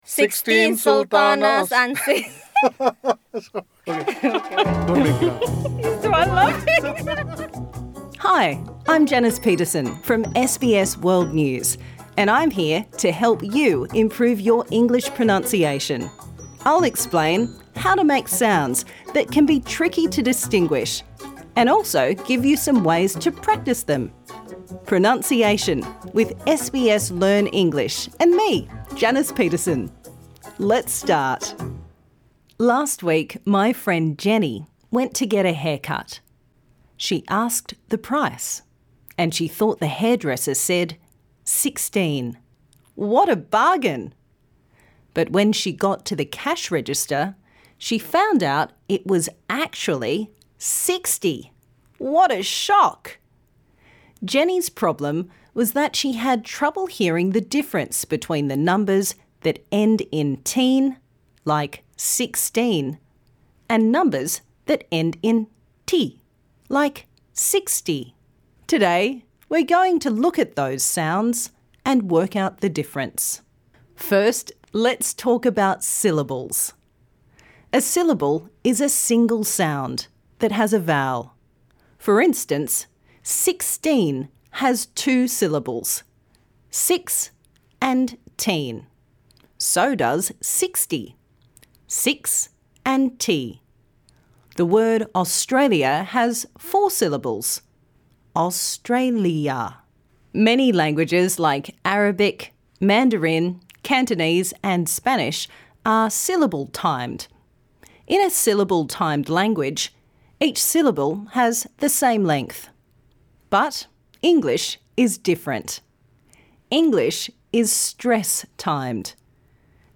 Text for Practise : I went to the store and bought thirteen tomatoes for thirty dollars, fourteen fish for forty dollars, fifteen forks for fifty dollars, etc. Minimal Pairs : ‘teen’ is the stressed syllable - it is long and clear and the /t/ is clearly pronounced: thirteen, fourteen... ‘ty’ is the unstressed syllable- it is short and quick and the ‘t’ is pronounced /d/: thirty, forty, fifty...
This lesson suits all learners looking to improve their pronunciation.